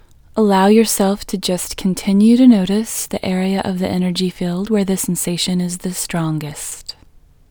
IN Technique First Way – Female English 8